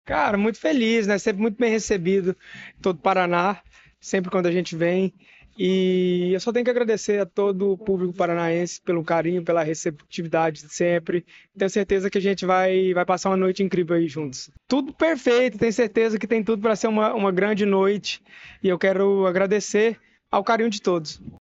Sonora do cantor Zé Felipe sobre o show no Verão Maior Paraná em Matinhos